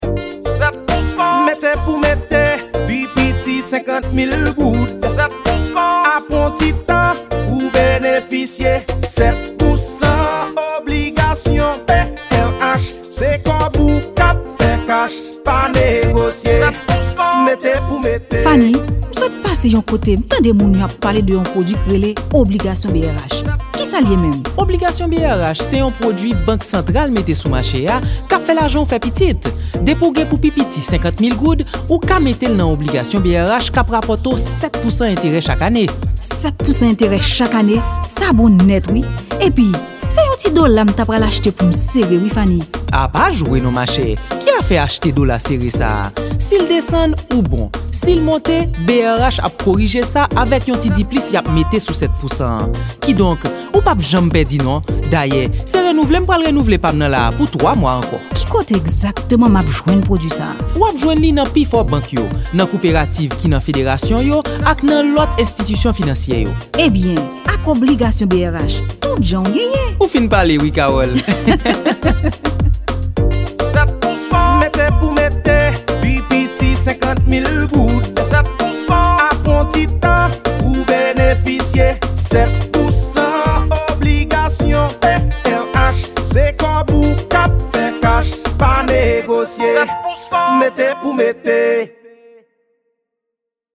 Bande sonore de la publicité
spot_audio_obligation.mp3